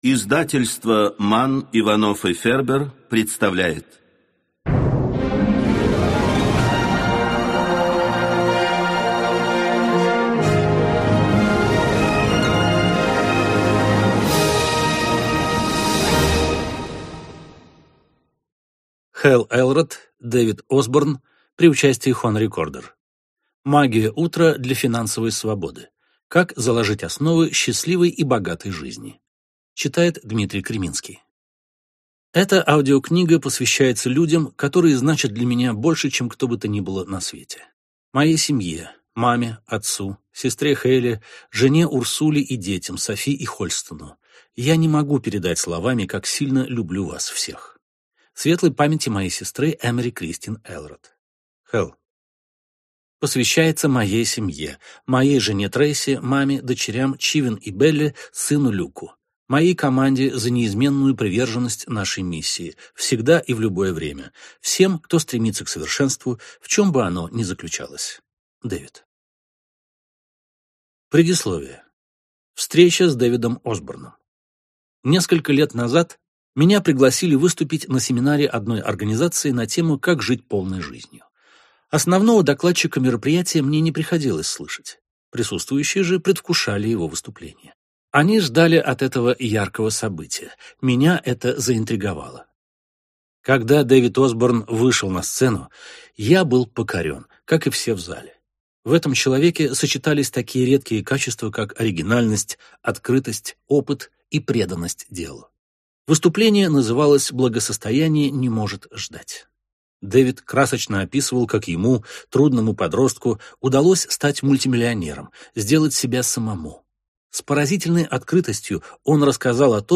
Аудиокнига Магия утра для финансовой свободы | Библиотека аудиокниг
Прослушать и бесплатно скачать фрагмент аудиокниги